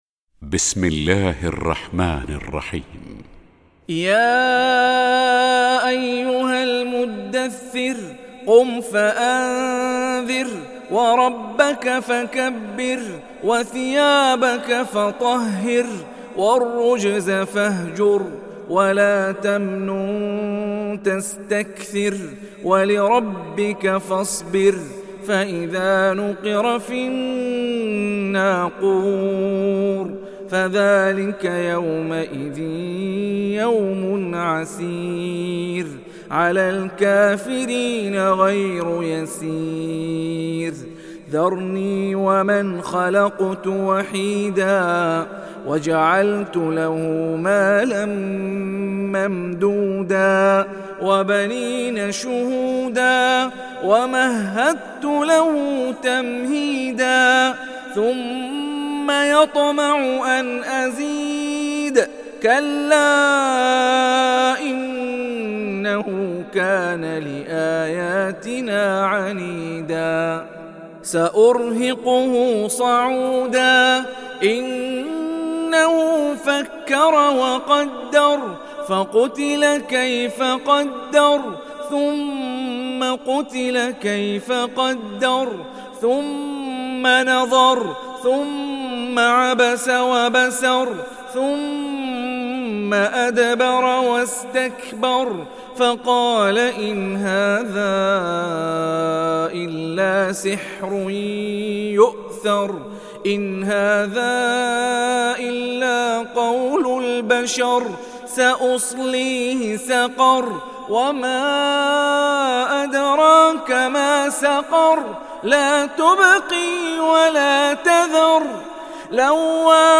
সূরা আল-মুদ্দাস্‌সির ডাউনলোড mp3 Hani Rifai উপন্যাস Hafs থেকে Asim, ডাউনলোড করুন এবং কুরআন শুনুন mp3 সম্পূর্ণ সরাসরি লিঙ্ক